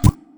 PickupSound Mixdown 1.wav